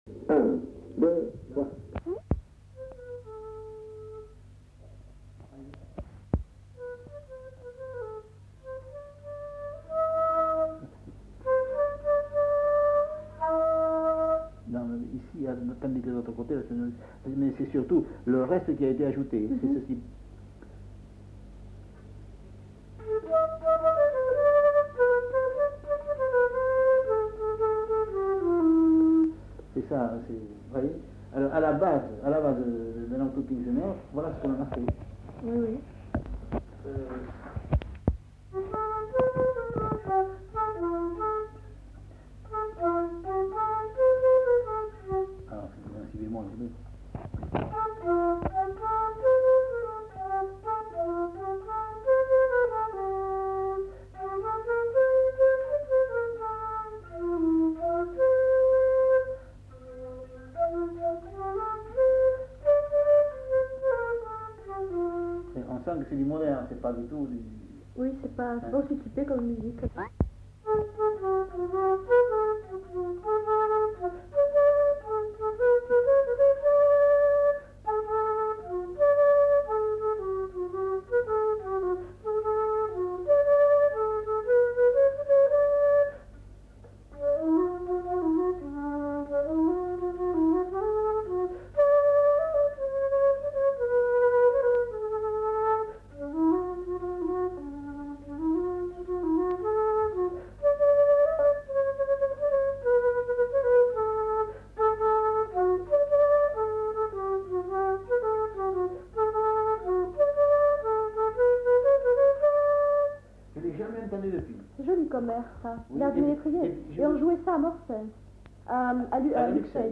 Aire culturelle : Marsan
Lieu : [sans lieu] ; Landes
Genre : morceau instrumental
Instrument de musique : flûte traversière